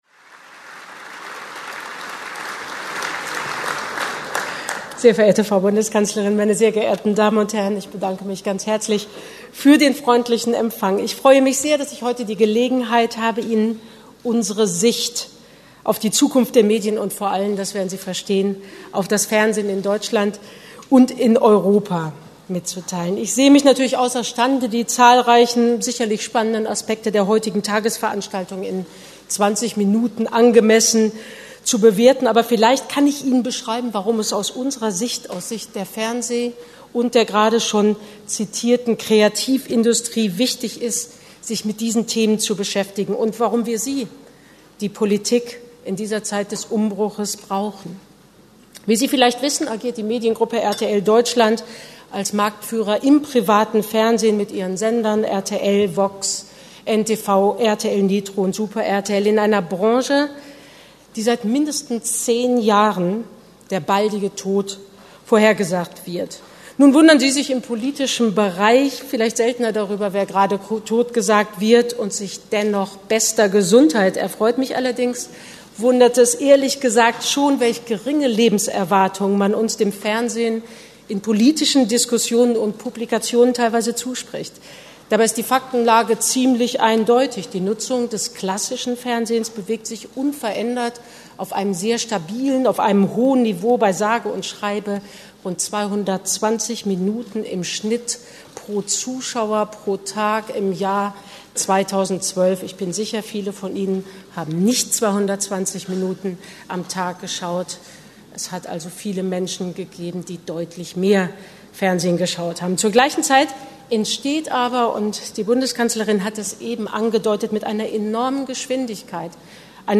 Was: Medienpolitische Grundsatzrede Wo: Berlin, CDU-Parteizentrale
Wer: Dr. Angela Merkel, Bundeskanzlerin, CDU-Vorsitzende